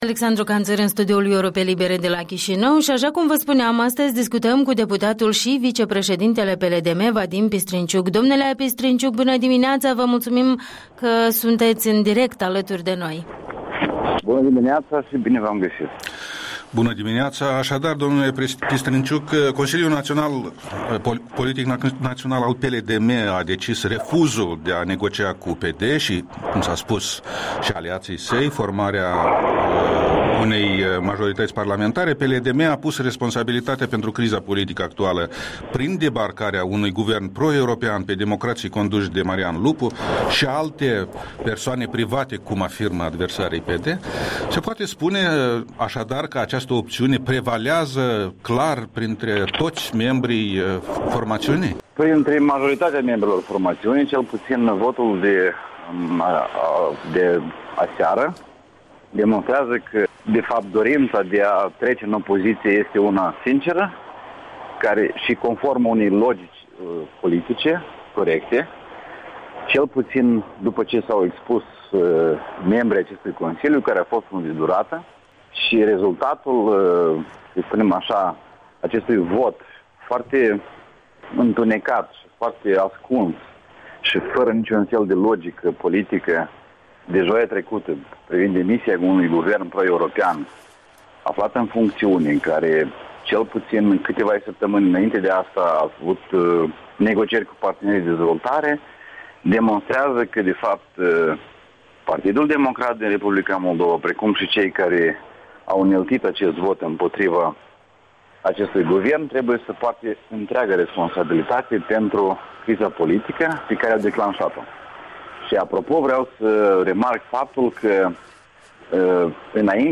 Interviul cu vicepreședintele Partidului Liberal Democrat.